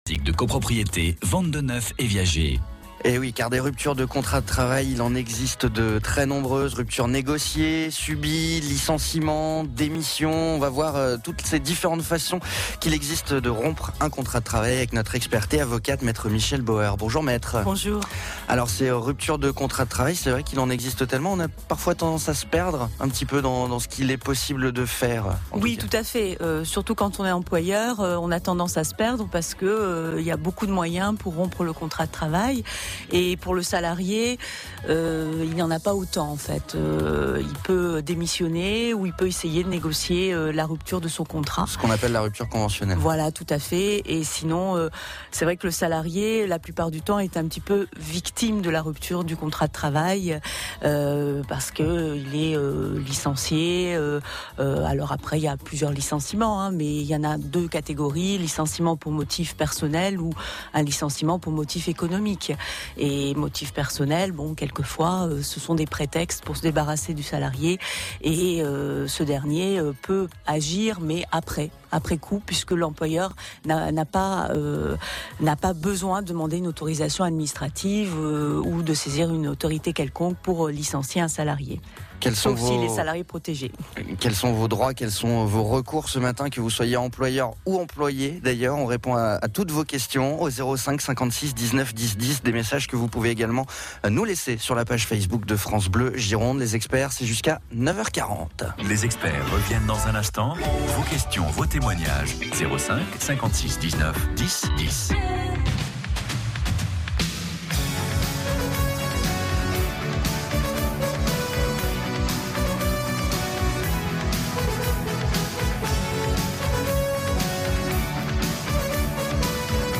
Je suis intervenue mercredi 30 novembre 2016 sur France Bleue Gironde pour répondre aux questions des auditeurs sur les ruptures du contrat de travail.